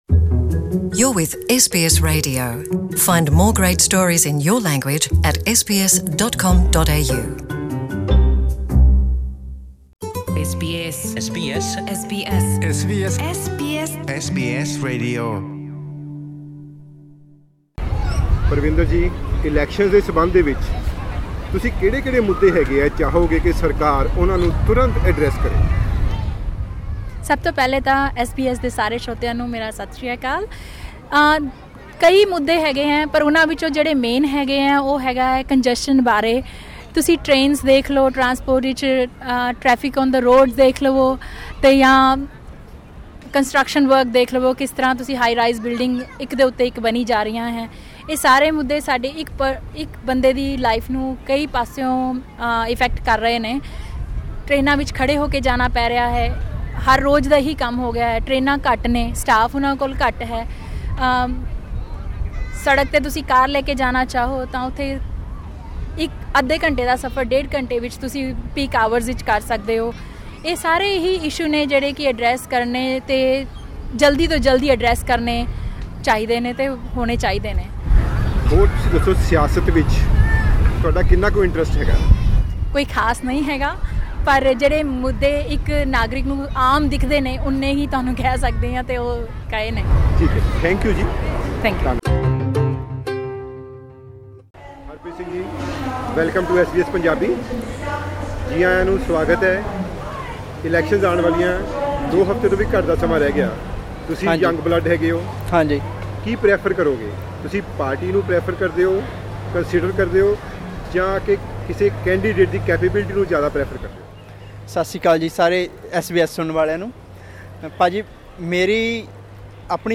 Hear what some Punjabi speaking voters in NSW told us, about what they think are the critical issues in the upcoming NSW elections
SBS Punjabi spoke to some community members to know their view point re oncoming elections.